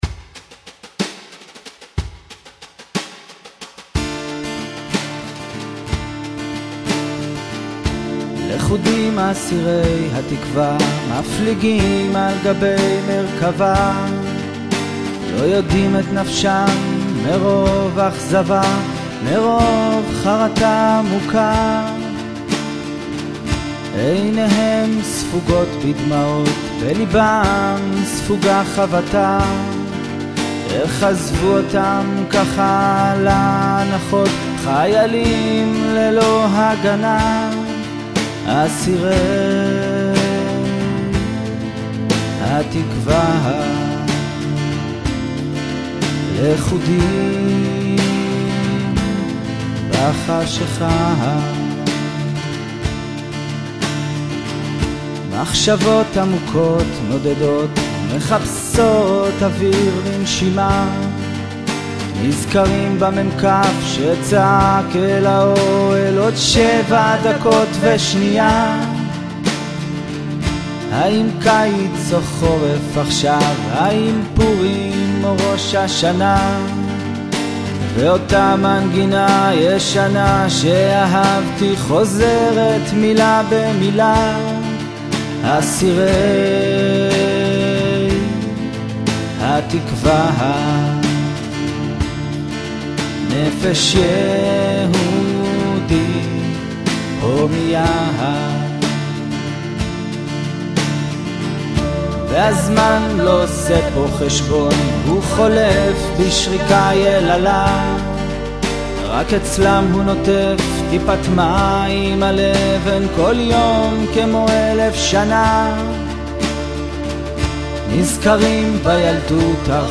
שר יפה!!
יש לך קול מעולה והמנגינה יפה ומילים סוף.
יש לך קול דומה קצת לאהוד בנאי.
אהבתי ממש את הנגינה של הגטרה- ממש יפה!
הליווי קצת ריק.
מכיוון שזו רק סקיצה אני מודע גם לדברים הטעונים שיפור.
מרגש ועצוב אבל ממש יפה